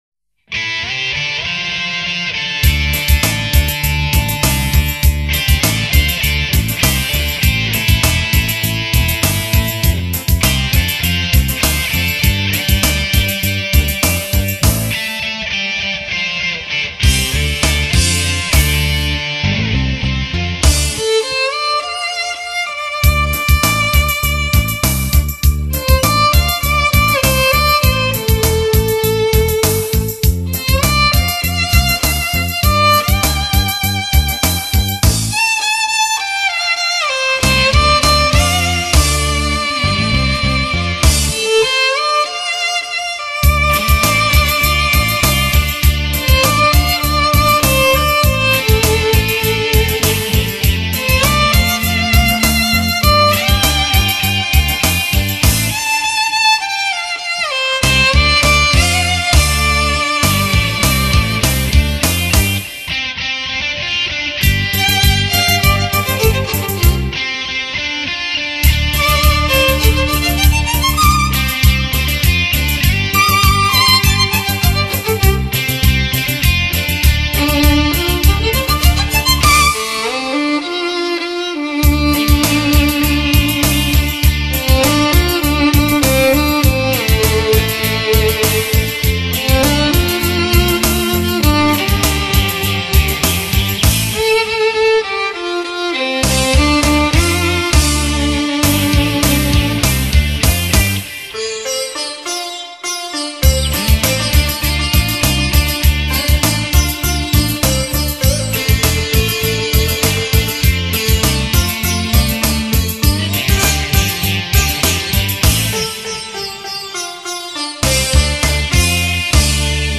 小提琴沿着时间的年轮轻吟浅唱。